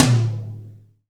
SYN_MIX_TOM.wav